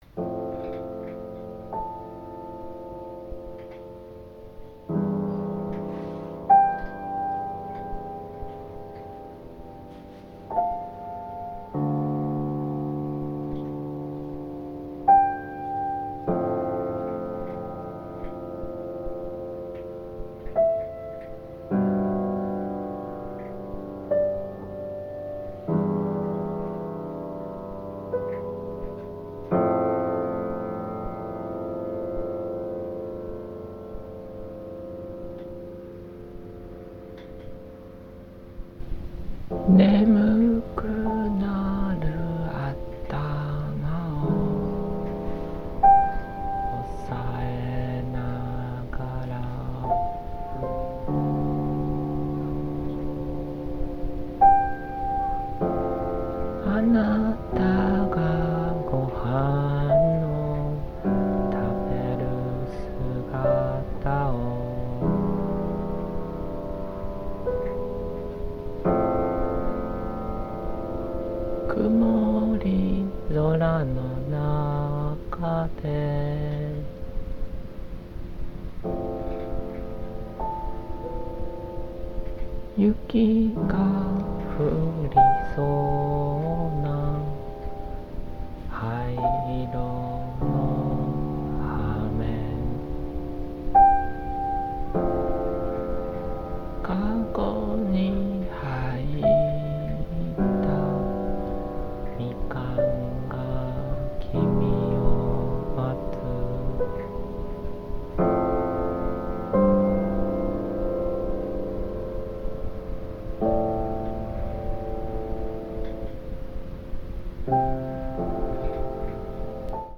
現代音楽やミニマリズムを想起させる叙情的なピアノ演奏と、余韻を強く感じさせるボーカルを軸に
ピアノには静寂、声にはざわめきが宿っていて、その両者が絶妙なバランスで拮抗しながら独特の詩情を産み出していますね！
録音上のノイズや音の背景から聴こえる物音なども、作品を貫く繊細な感性を際立たせるように作用していて◎！